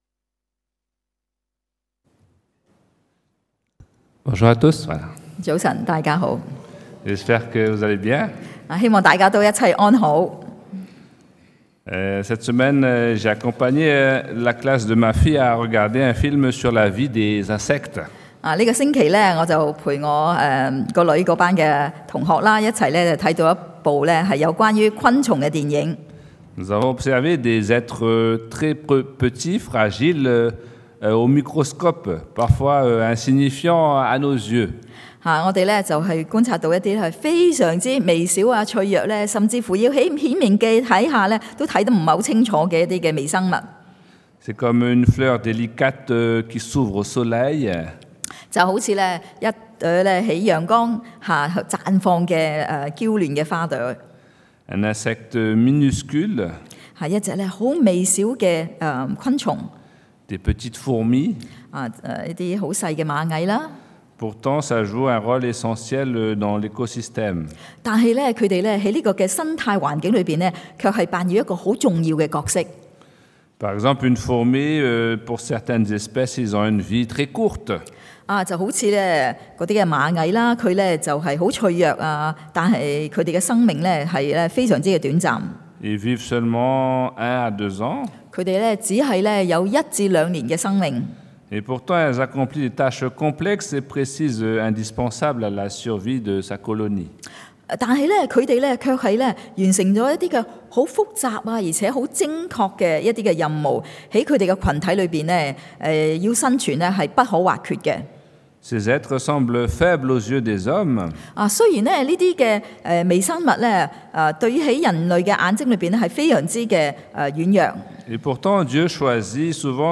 Predication du dimanche « Le chemin de la victoir